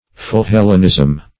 Search Result for " philhellenism" : Wordnet 3.0 NOUN (1) 1. admiration for Greece and the Greeks and Greek customs ; The Collaborative International Dictionary of English v.0.48: Philhellenism \Phil*hel"len*ism\, n. Love of Greece.